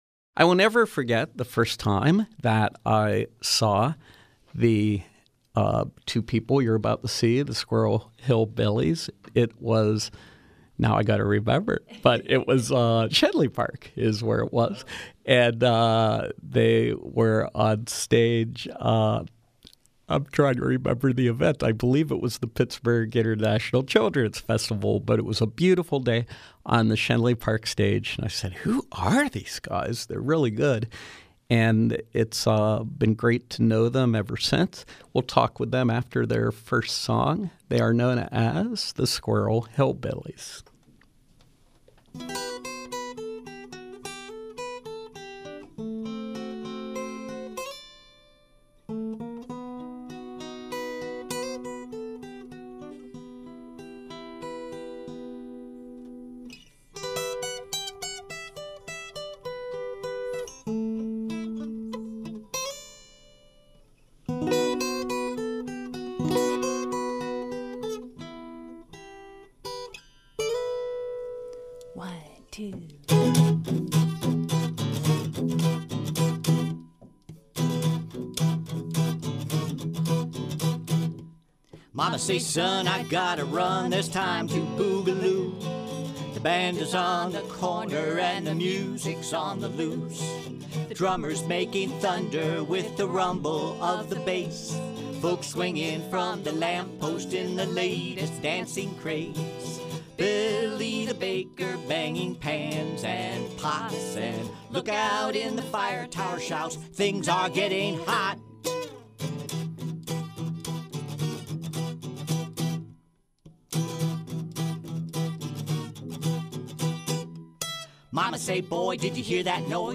Live music with acoustic folk duo